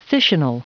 Prononciation du mot fissional en anglais (fichier audio)
Prononciation du mot : fissional